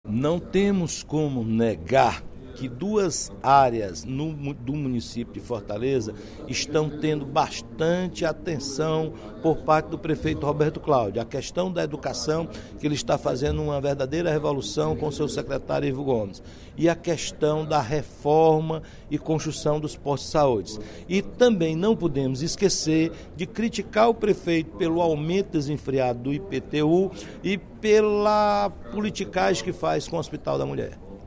Durante o primeiro expediente da sessão plenária da Assembleia Legislativa desta sexta-feira (28/02), o deputado Roberto Mesquita (PV) reconheceu o êxito de duas das áreas da gestão do prefeito Roberto Cláudio.